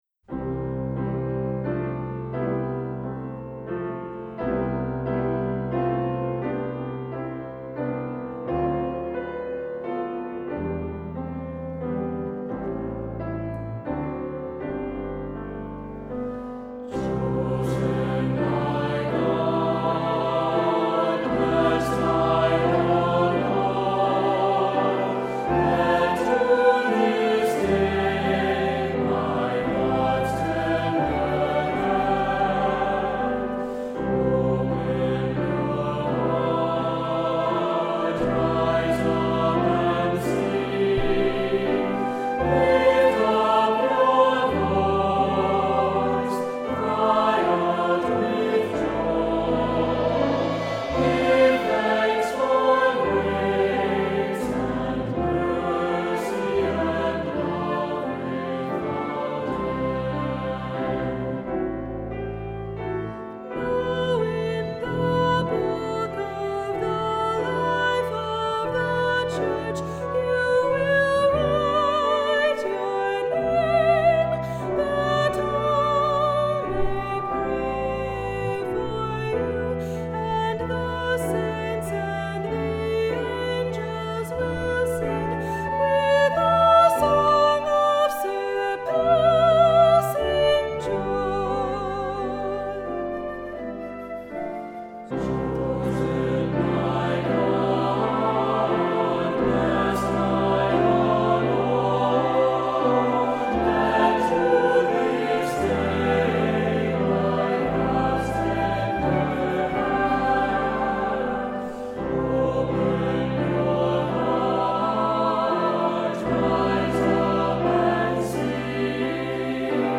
Accompaniment:      Keyboard, C Instrument
Music Category:      Christian
For cantor or soloist